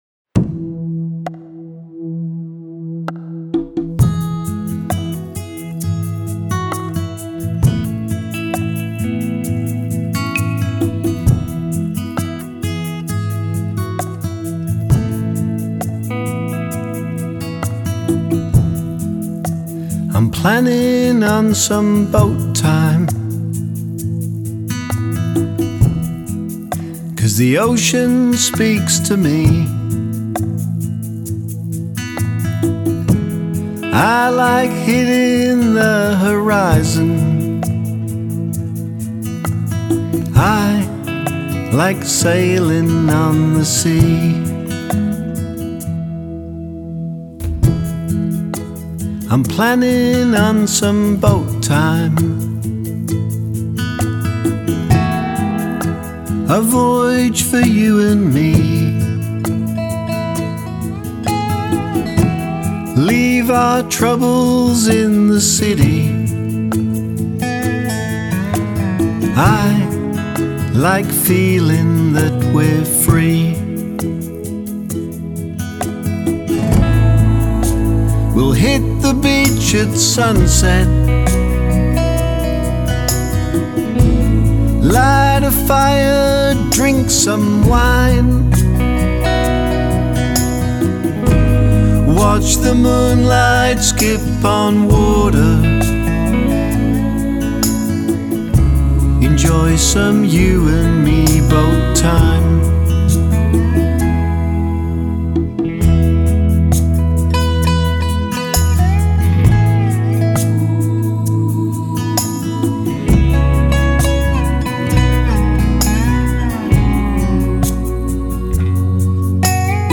a fresh contemporary sound
beautiful emotive ballad
Dobro
Backing Vocals